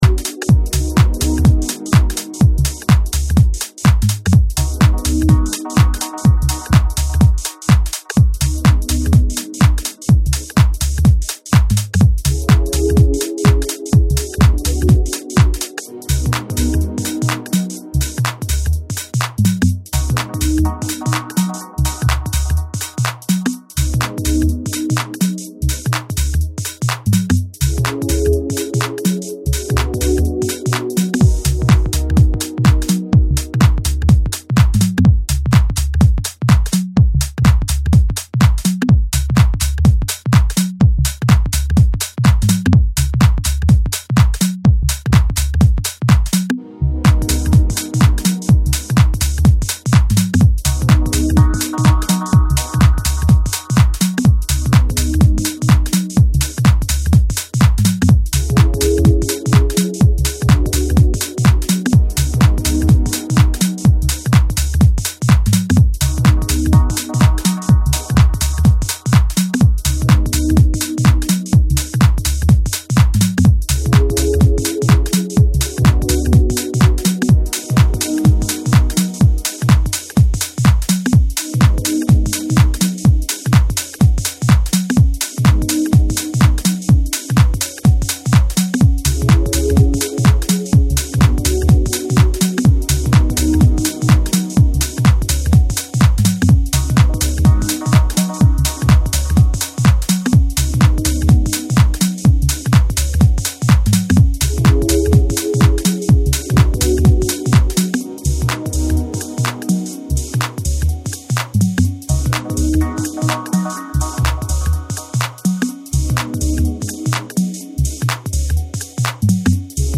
stripped-down and atmospherics tracks